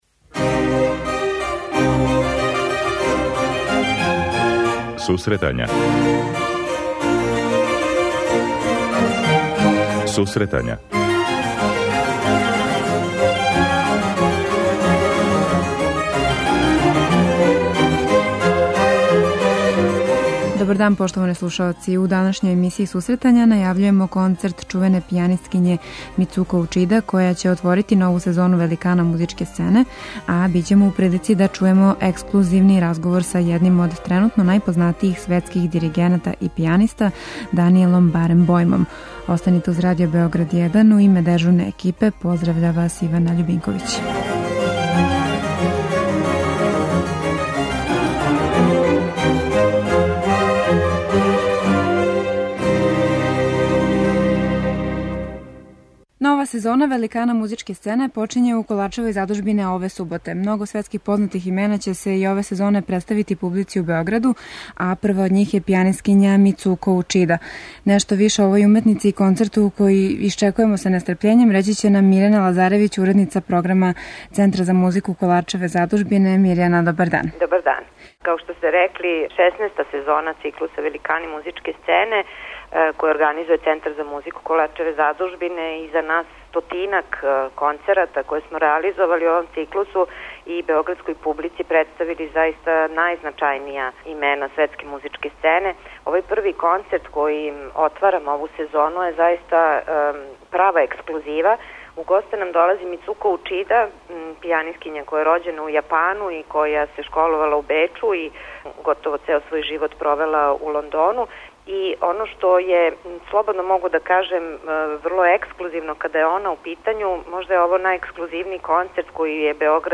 У данашњој емисији ћемо ексклузивно слушати једног од најпознатијих диригената и пијаниста данашњице - Данијела Баренбојма који је 18. августа наступио у оквиру 63. Дубровачких летњих игара.